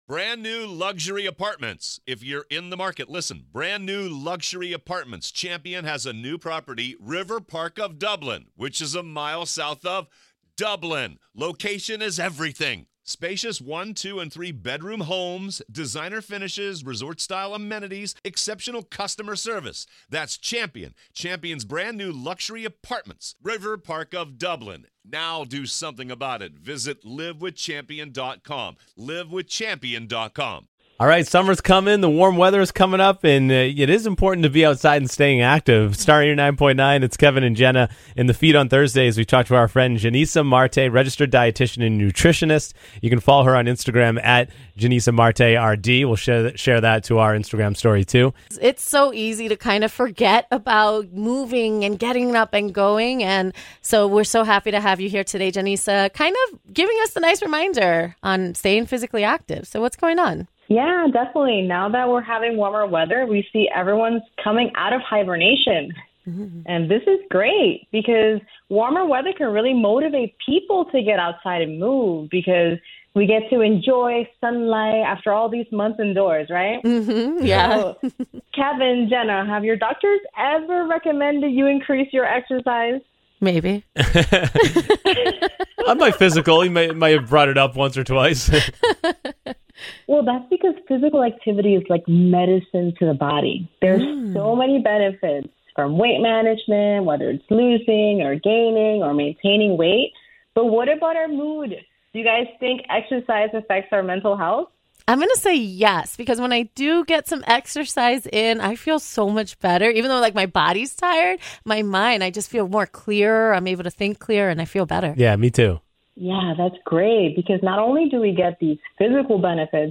chat with an expert about all things health and wellness.